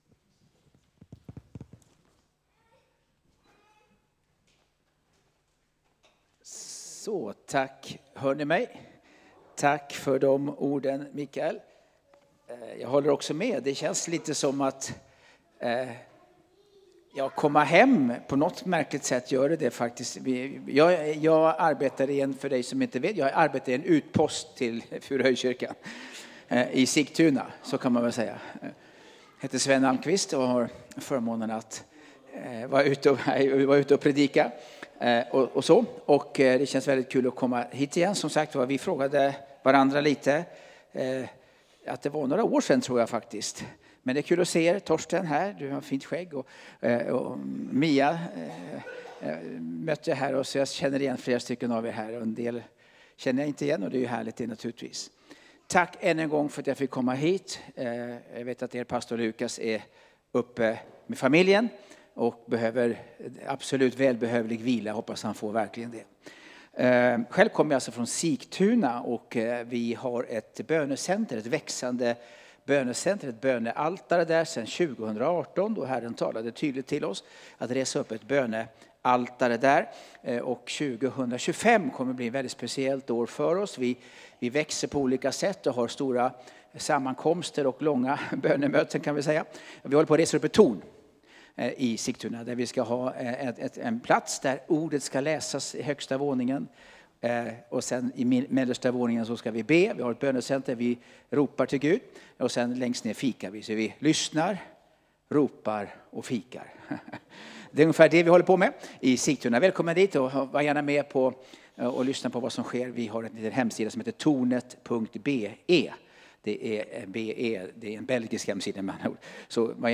Furuhöjdskyrkan, Alunda Gudstjänst